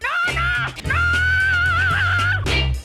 beat.aiff